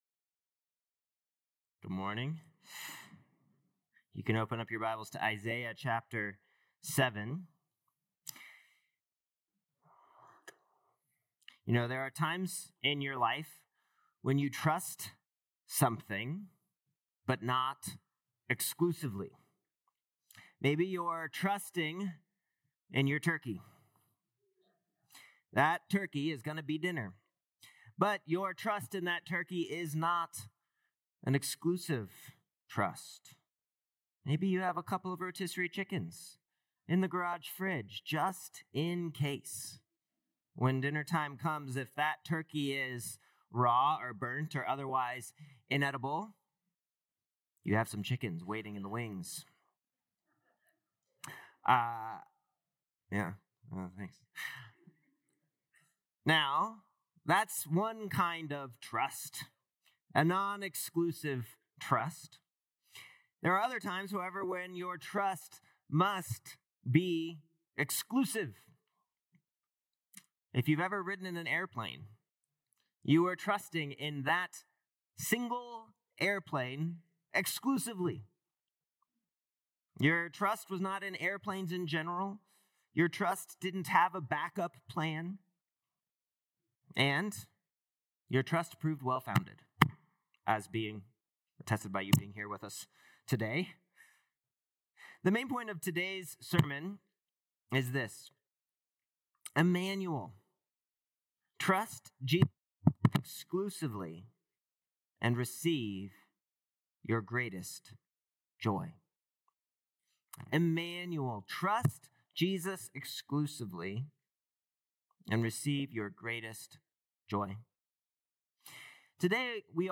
Dec 1st Sermon